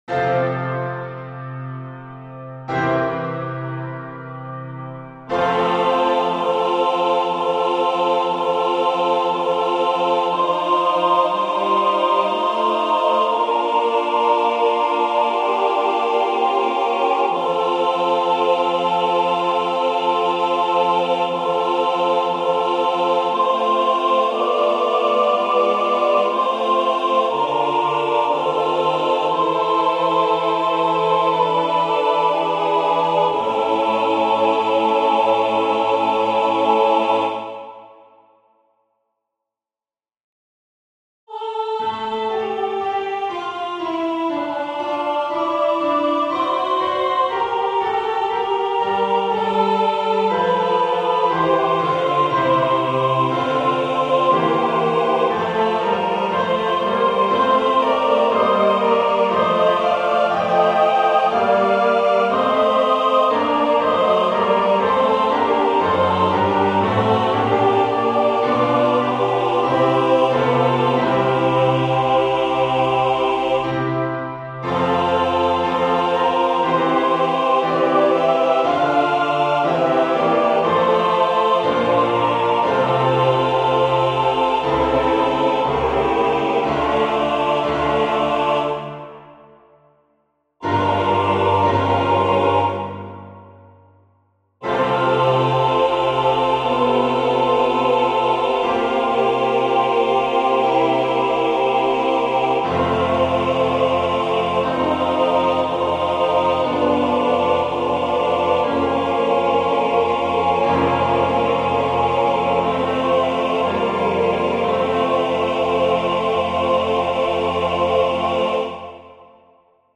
FF:HV_15b Collegium musicum - mužský sbor